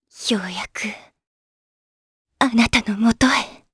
Hilda-Vox_Dead_jp.wav